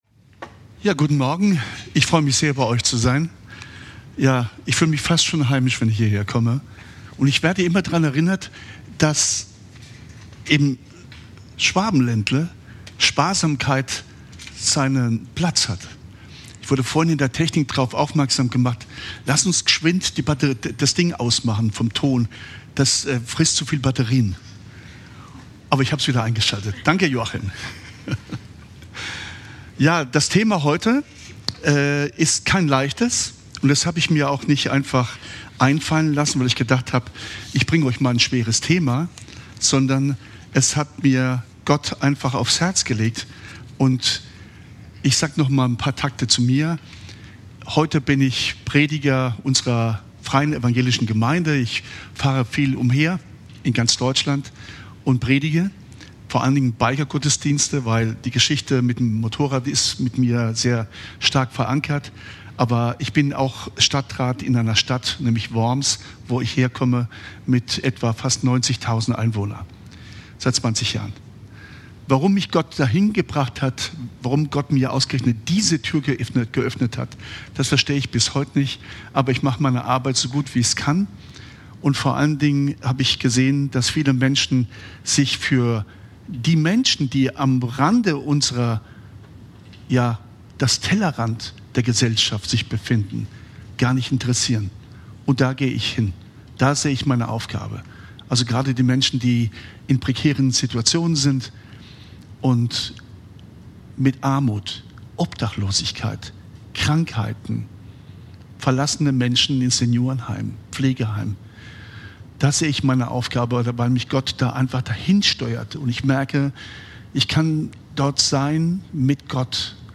Crossline-Predigt vom 22.03.2026